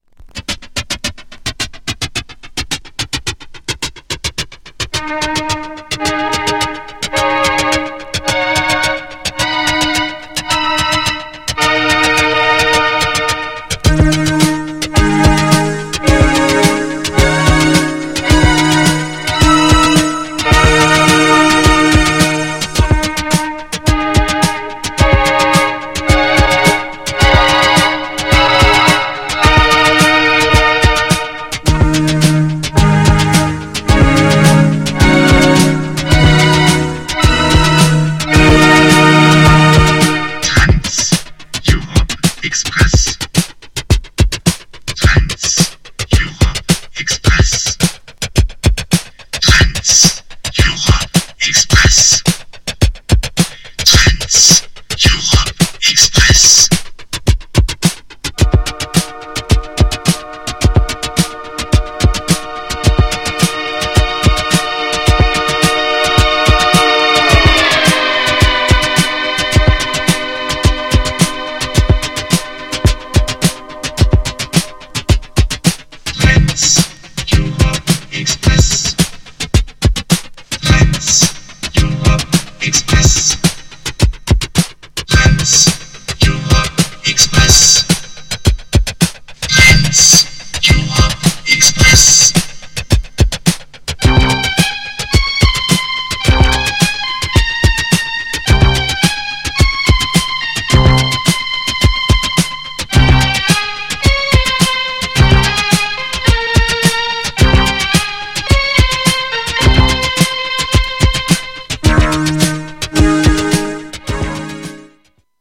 GENRE Dance Classic
BPM 101〜105BPM